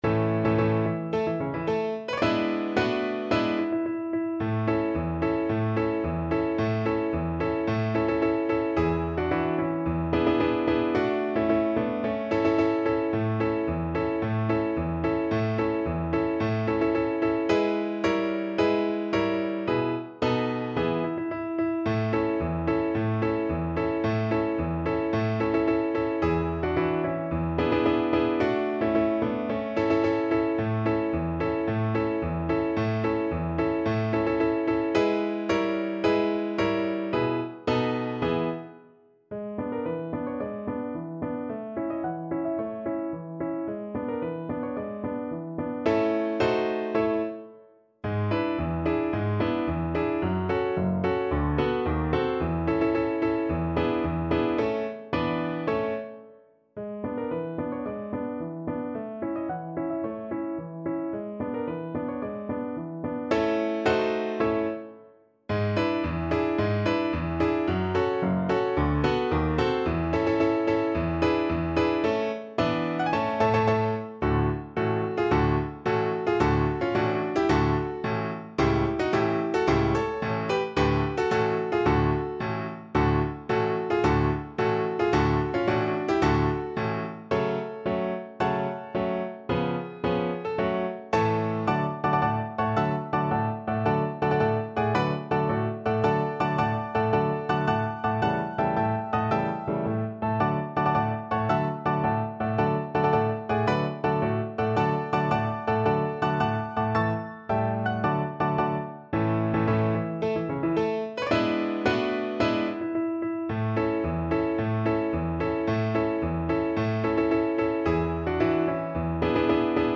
Play (or use space bar on your keyboard) Pause Music Playalong - Piano Accompaniment Playalong Band Accompaniment not yet available transpose reset tempo print settings full screen
A major (Sounding Pitch) (View more A major Music for Violin )
March =c.110
Classical (View more Classical Violin Music)